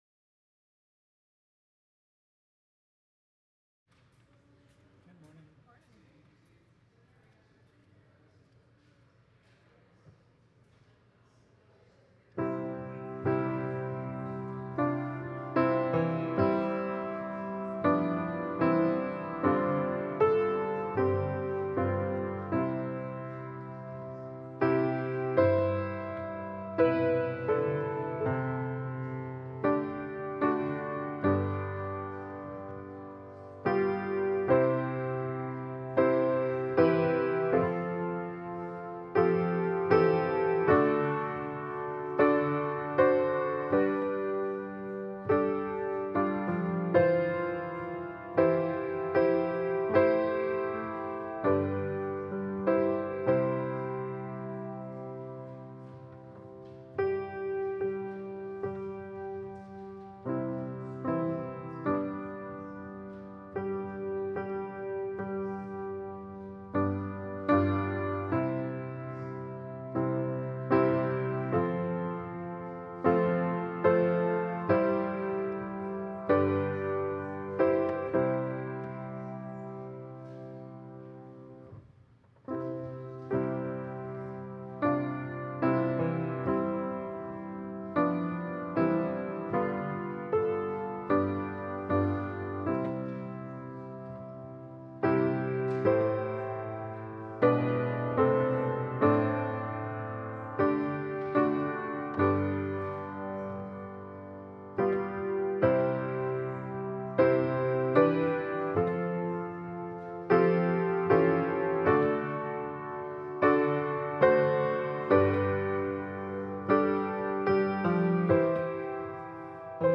Passage: Psalm 115 Service Type: Sunday Service